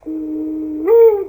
dove.wav